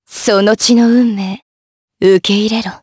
Lewsia_B-Vox_Skill6_jp_b.wav